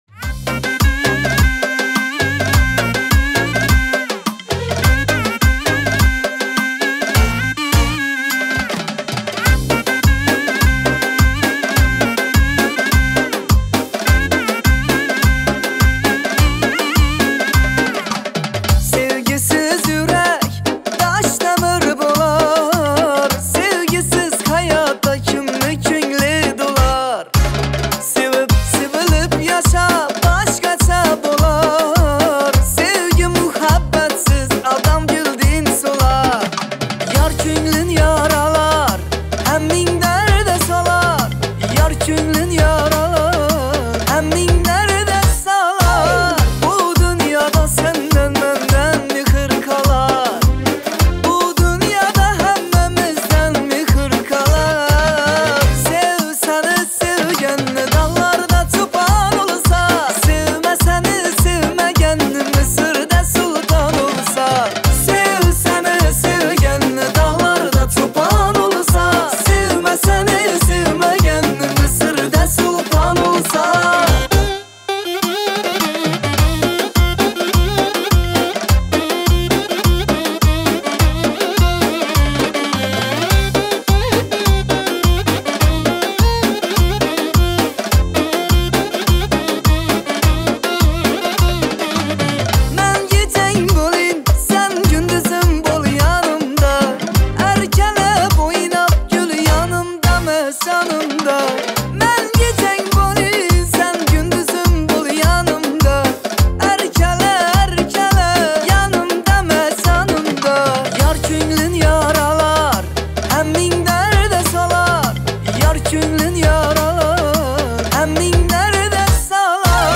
Узбекская песня